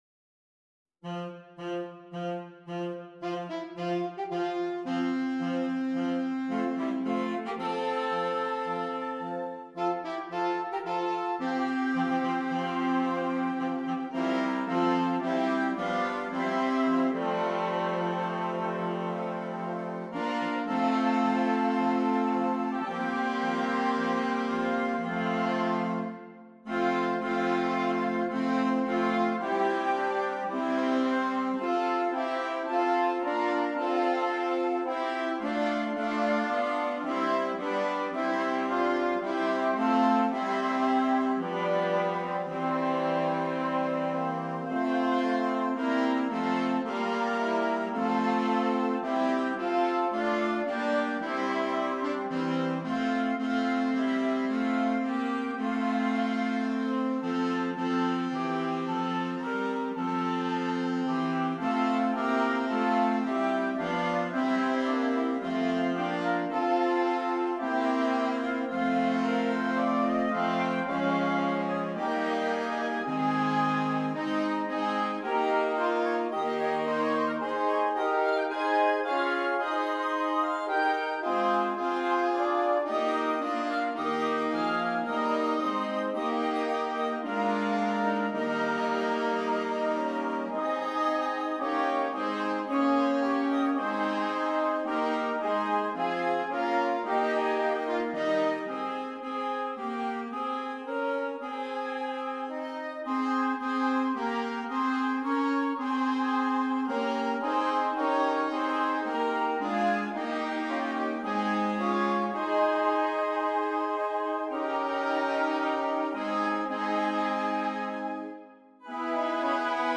Interchangeable Woodwind Ensemble
Traditional Carol